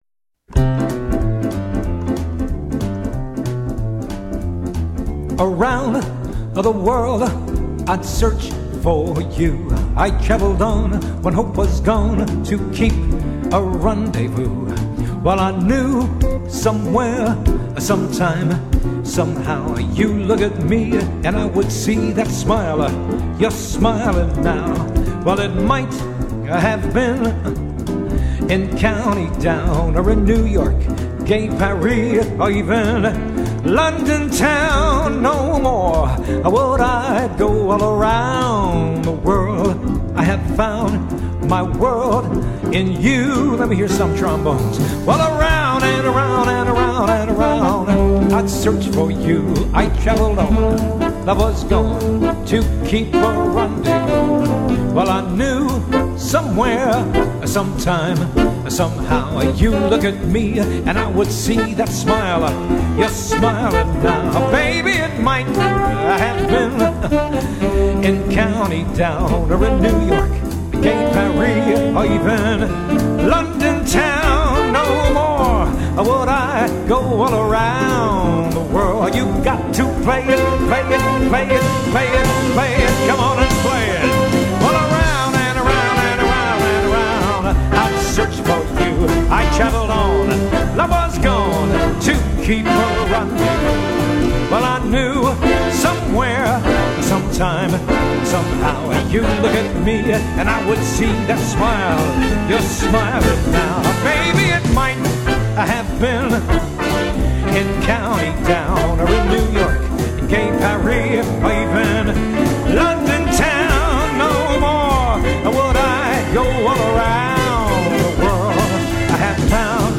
It has a fabulous swing.